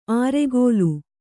♪ āregōlu